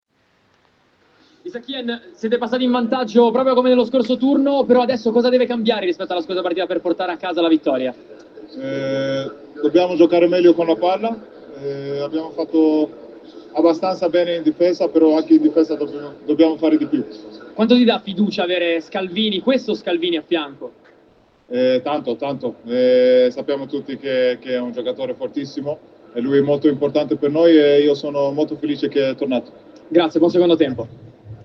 Hien intervistato da Dazn a fine primo tempo si è detto felice ma concentrato. Lo svedese ha sottolineato come la squadra può fare meglio sia in attacco che in difesa ed è molto felice per il rientro in reparto di Scalvini dopo la scorsa stagione.